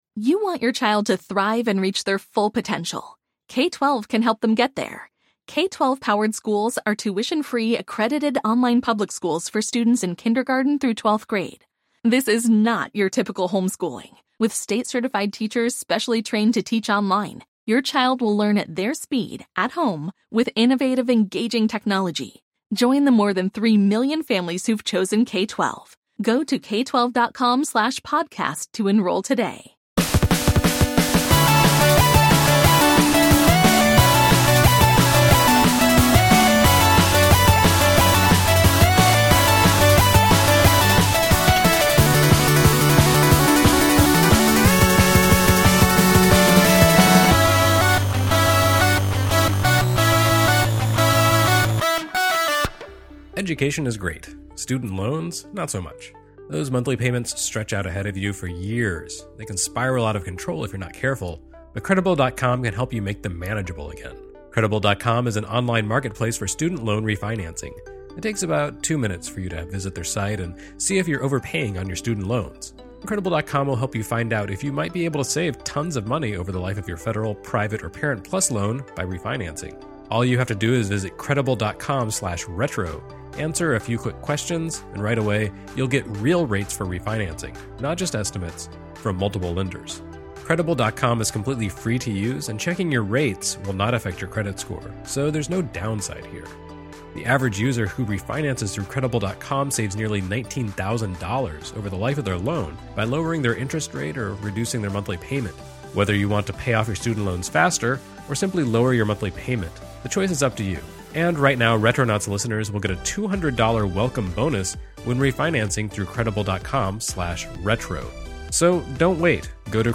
the two-man crew tackles your most burning questions about Metal Gear